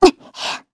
Xerah-Vox_Jump_kr.wav